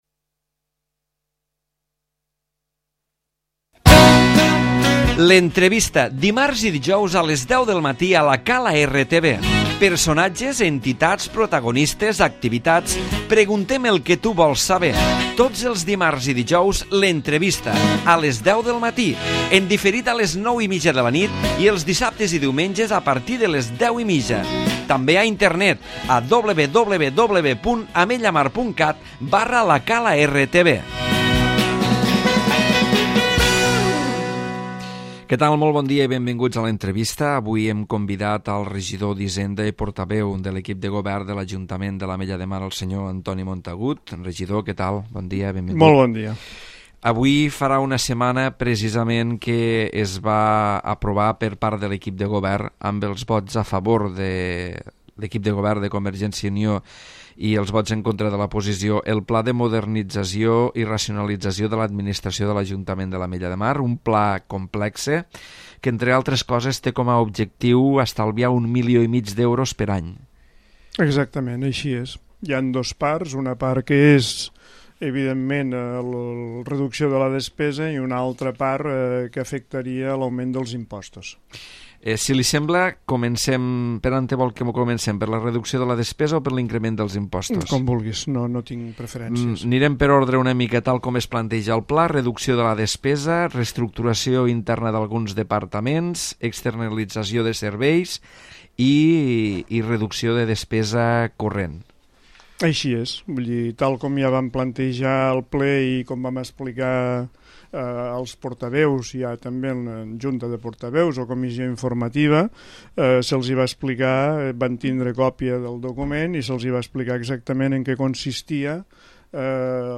L'Entrevista
Antoni Montagut, regidor d'hisenda i portaveu de l'equip de govern parla sobre el Pla de Modernització i Racionalització de l'adminsitració de l'Ajuntament de l'Ametlla de Mar.